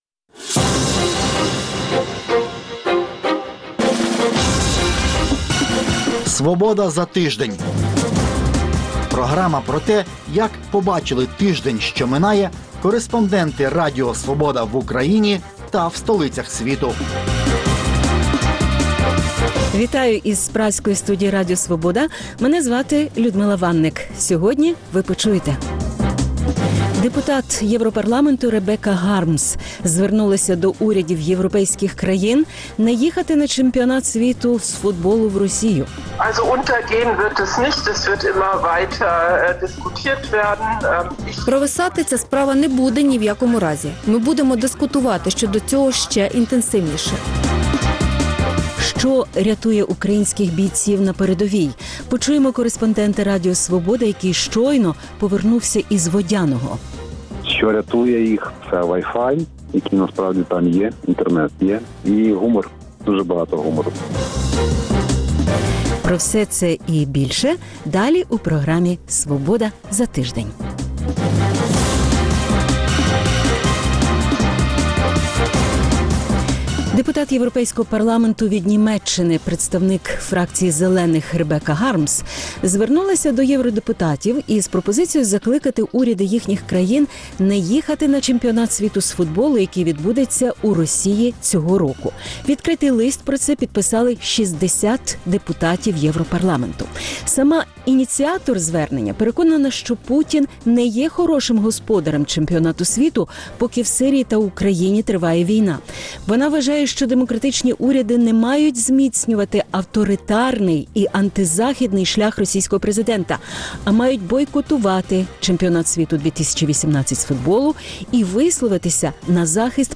Інтерв’ю з депутатом Європарламенту Ребеккою Гармс, яка звернулась до урядів європейських країн не їхати на чемпіонат світу з футболу в Росію. Що рятує українських бійців на передовій – почуємо кореспондента Радіо Свобода, який щойно повернувся з Водяного. Події у Вірменії і як на них відреагували всевітньо-відомі вірмени з діаспори.